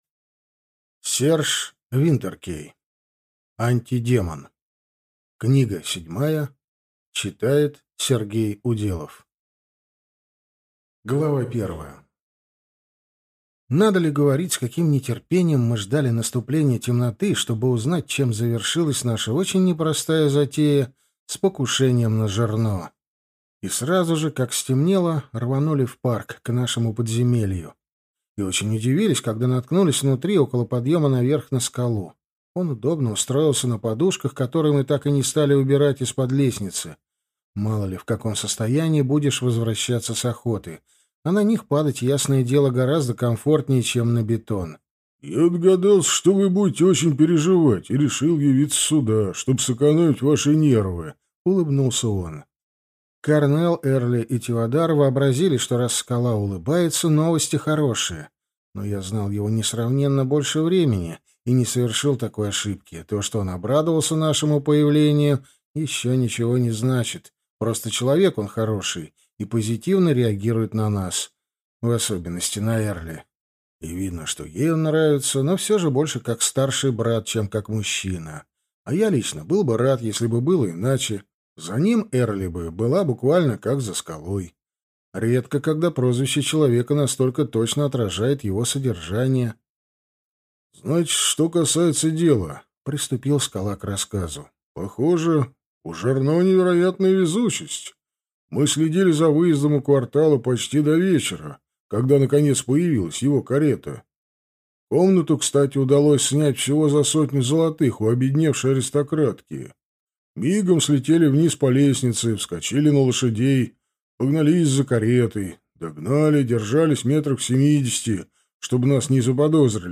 Аудиокнига Антидемон.